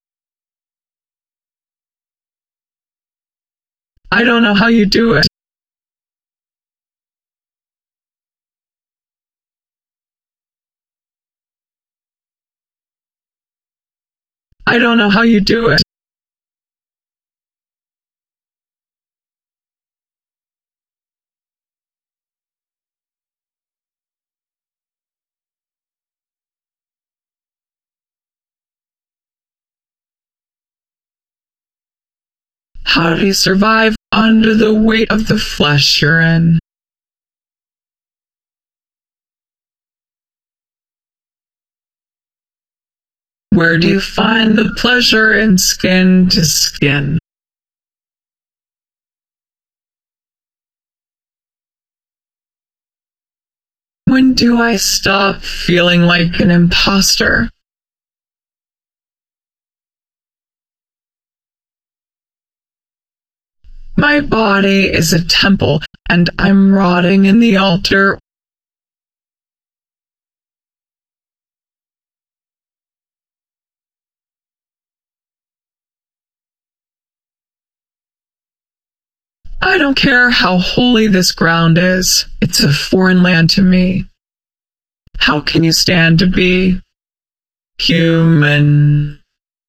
I used an old fashioned Text-to-Speech-to-Autotune method for the vocals, which maybe compliments the "I don't feel human" theme?
idk how u do it VOX DRY.flac